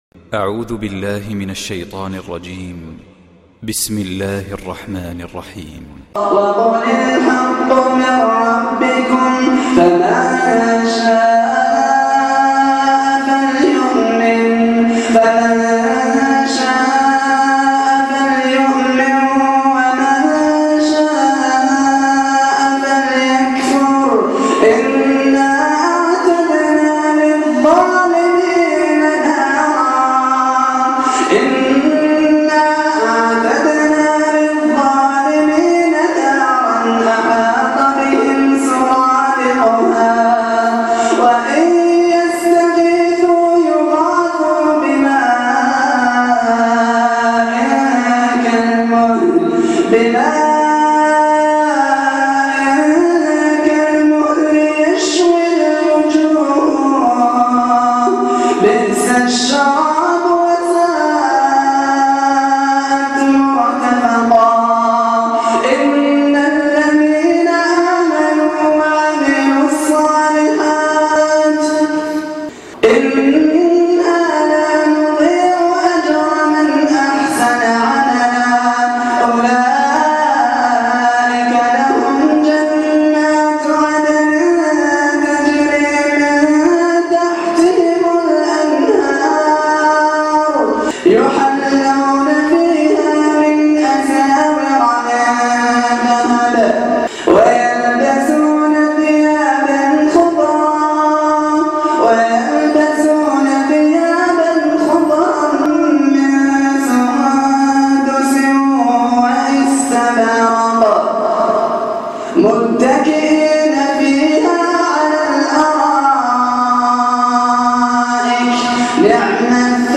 القرآن الكريم صوت خاشع ومؤثر للقارئ الجزائري